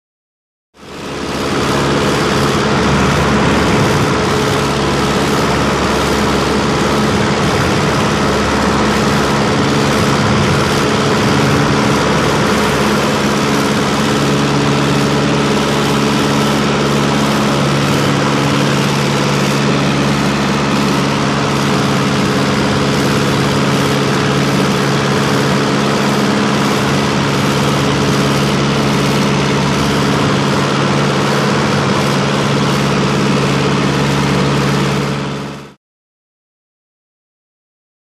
Tiger Moth|Interior | Sneak On The Lot
Prop Plane; Interior; Tiger Moth Prop Aircraft Constant In Flight.